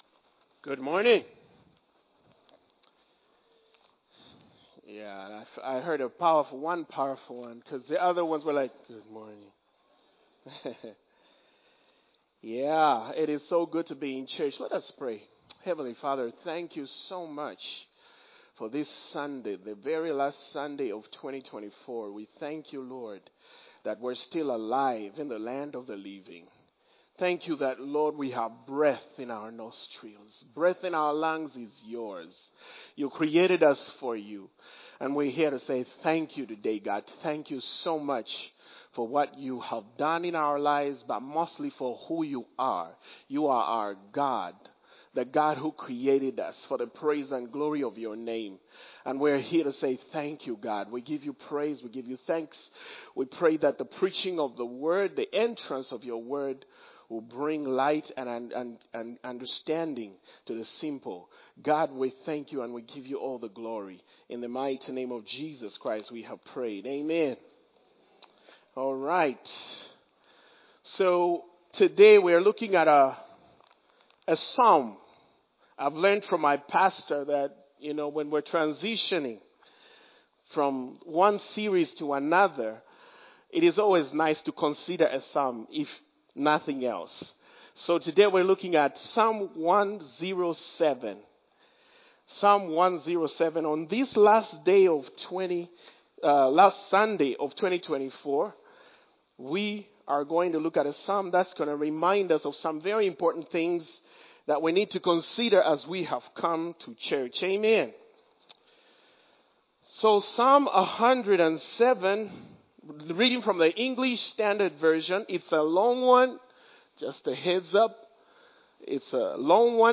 A message from the series "Individual Sermons."